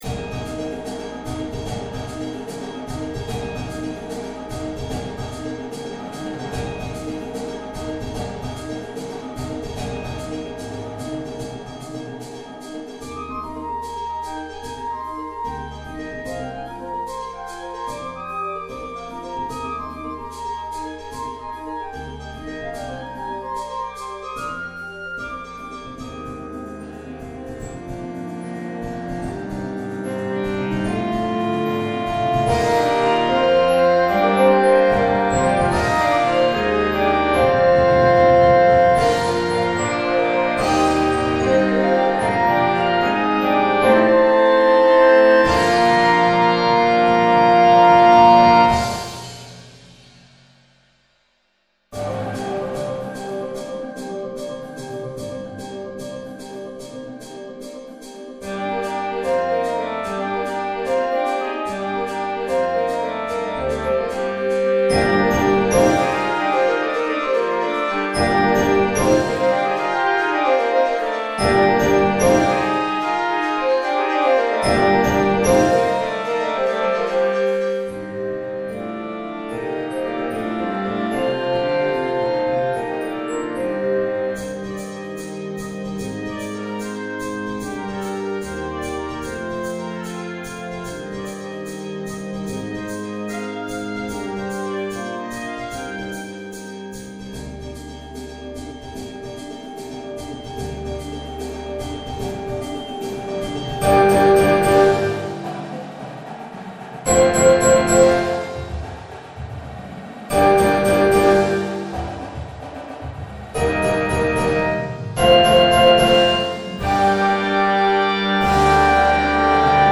Inspired by African folk melodies and ethnic rhythms